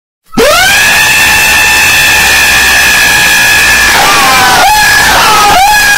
Screaming Bird Meme (LOUD).mp345